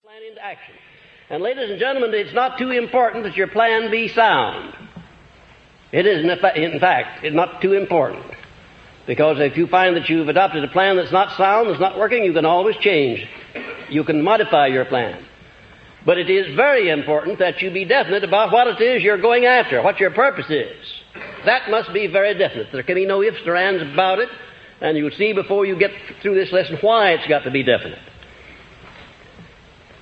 The lost recordings of Napoleon Hill lectures as he personally promoted his philosophy of wealth and achievement, as written about in his famous books Law of Success and Think and Grow Rich.